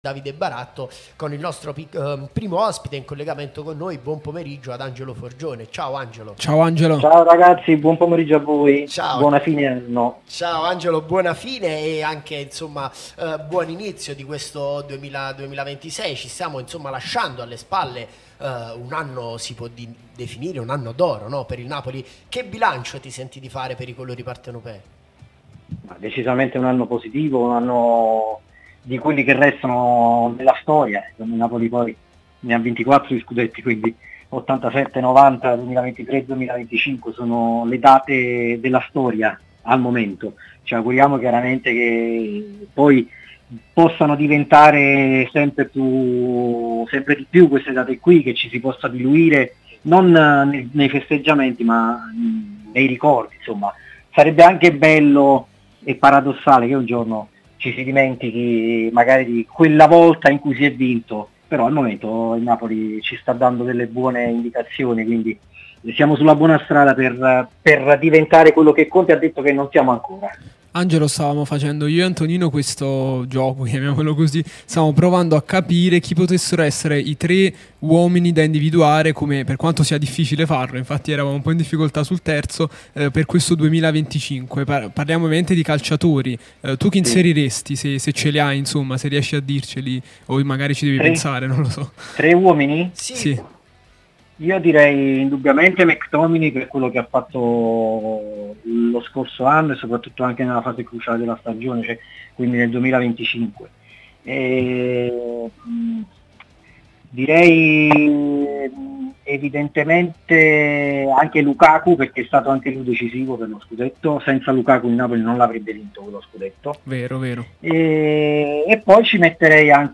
intervenuto nel corso di Napoli Talk, trasmissione sulla nostra Radio Tutto Napoli, prima radio tematica sul Napoli, in onda tutto il giorno